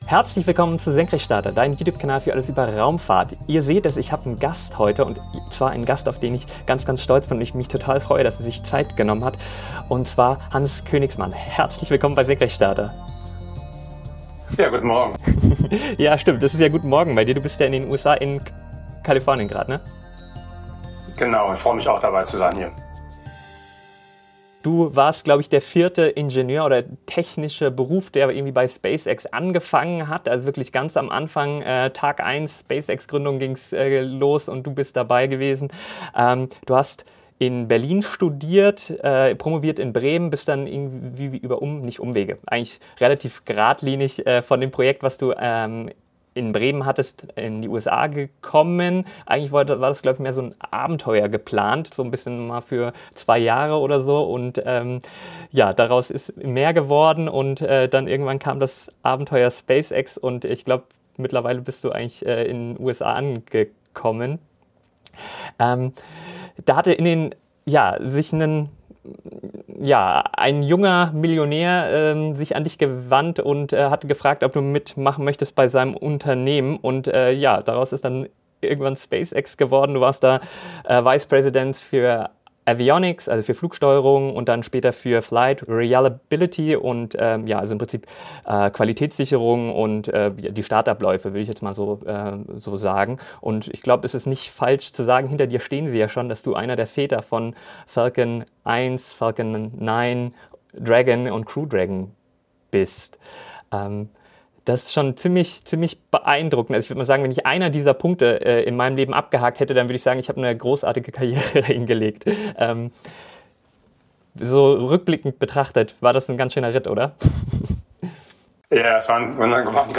Interview mit Ex SpaceX Topmanager